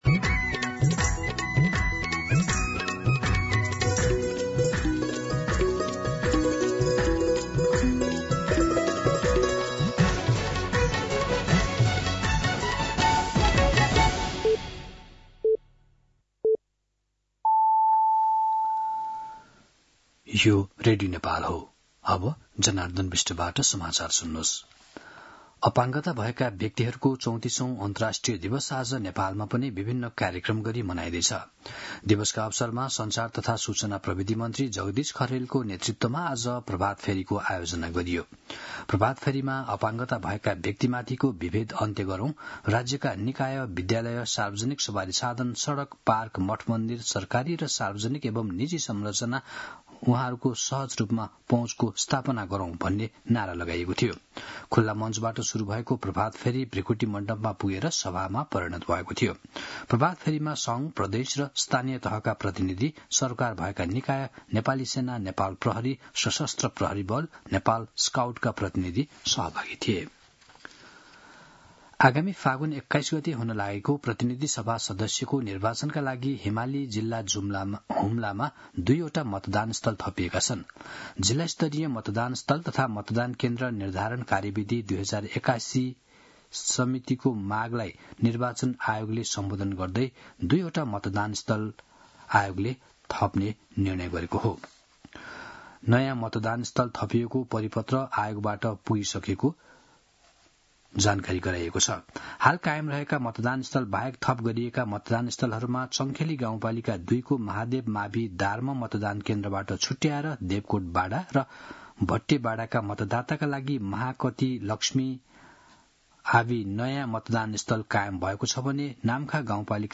दिउँसो १ बजेको नेपाली समाचार : १७ मंसिर , २०८२